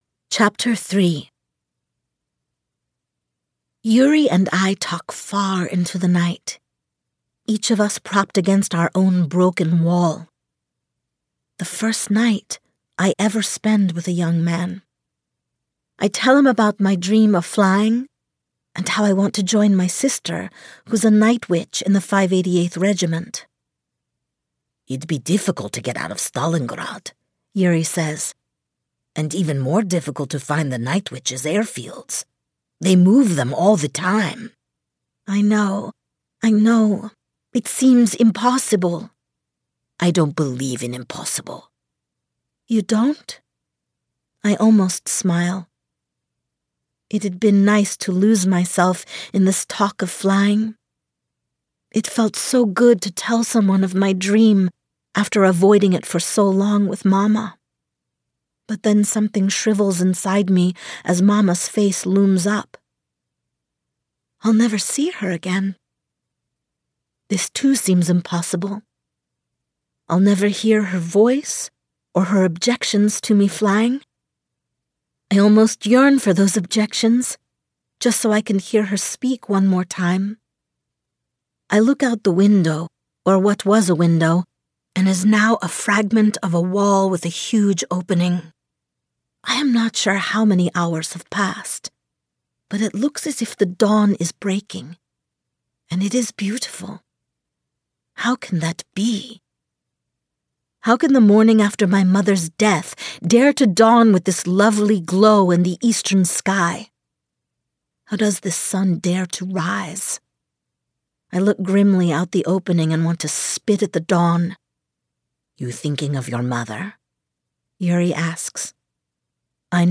new york : voiceover : commercial : women